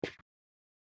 pickup.mp3